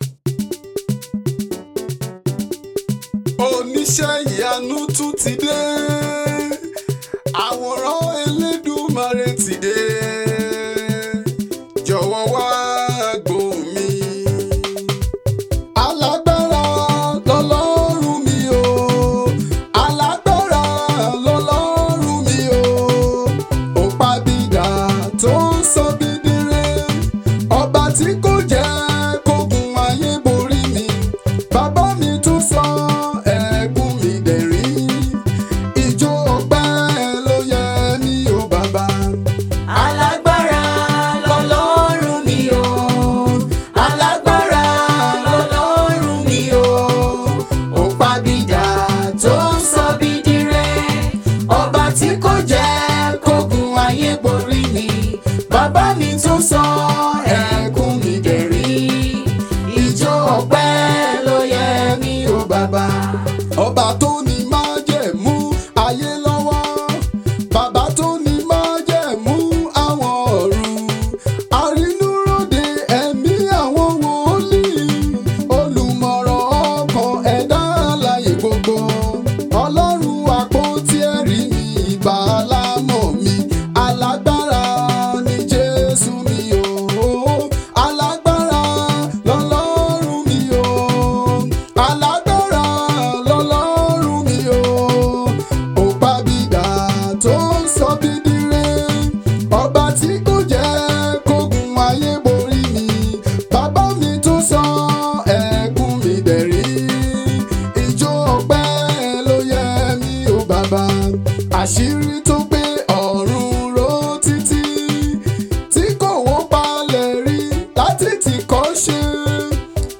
heartfelt gospel song